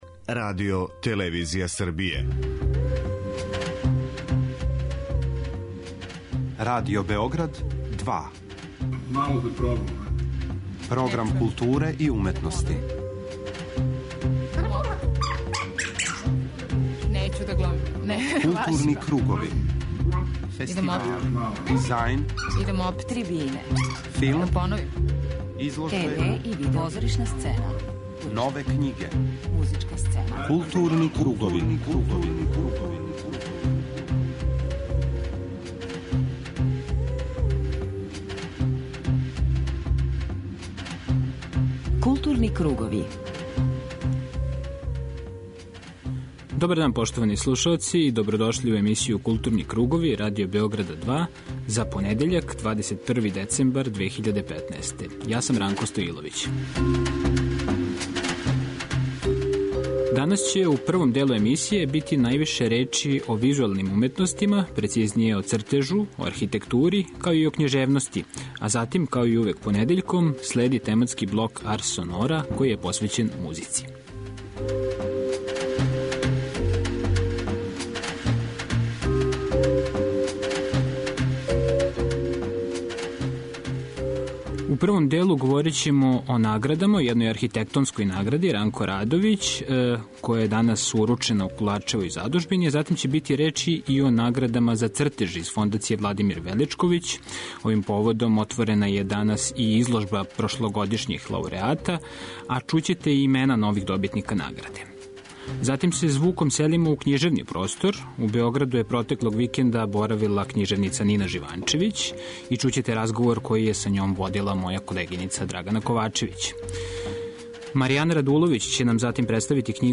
преузми : 41.19 MB Културни кругови Autor: Група аутора Централна културно-уметничка емисија Радио Београда 2.